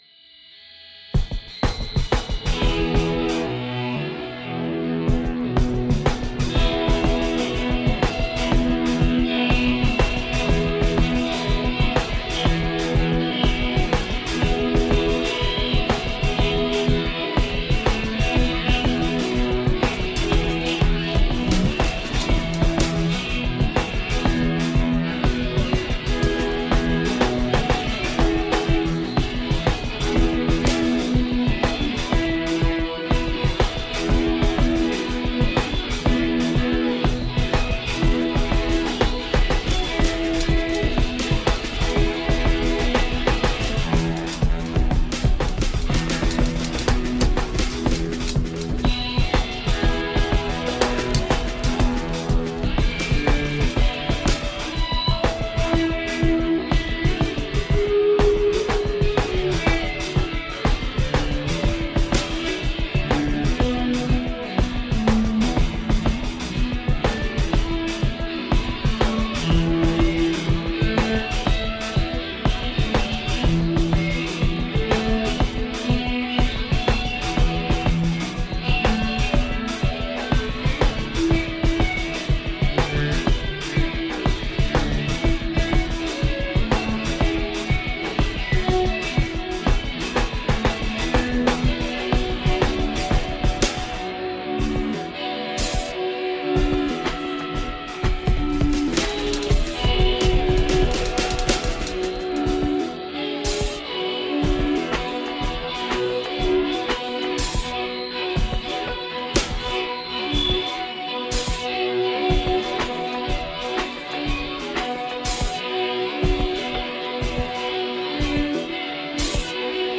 My best instrumental music from 2005